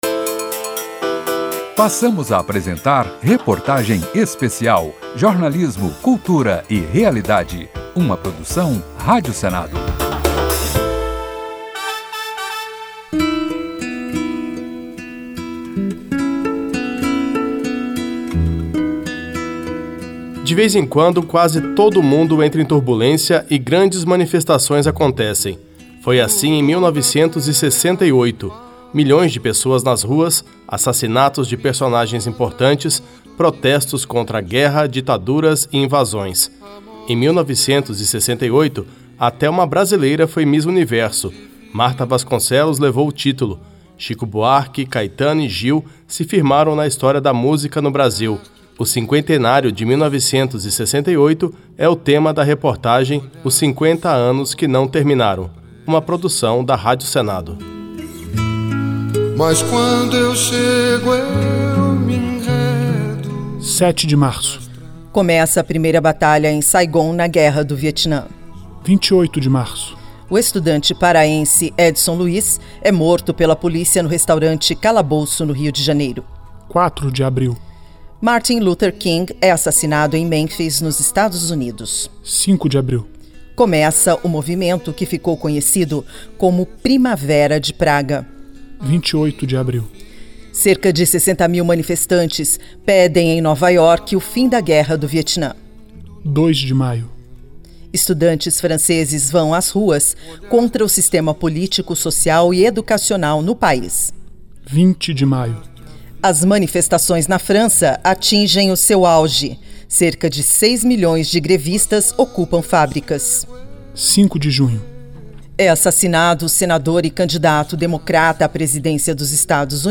Rádio Senado apresenta reportagem sobre os 50 anos de 1968
A reportagem especial traz depoimentos do ex-presidente do Senado, José Sarney e dos também ex-senadores Pedro Simon e Arthur Virgílio, entre outros.